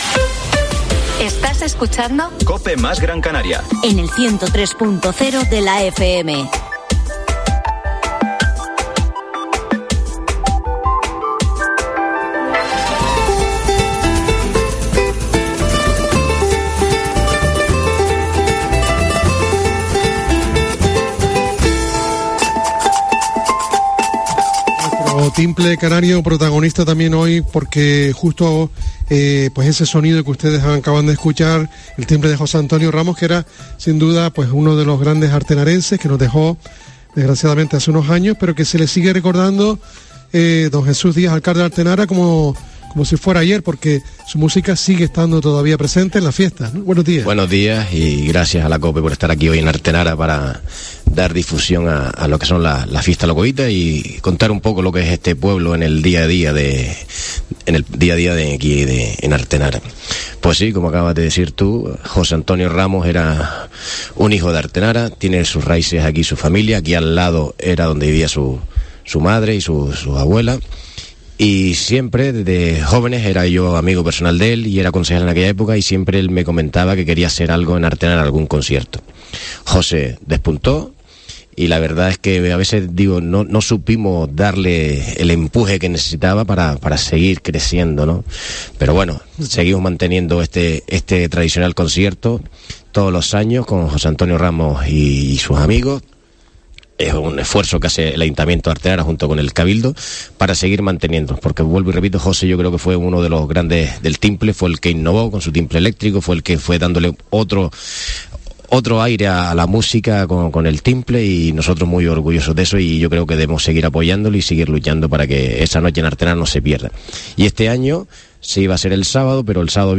La mañana de COPE más Gran Canaria se ha desplazado hasta el municipio de Artenara con motivo de motivo de las fiestas de La Cuevita 2023.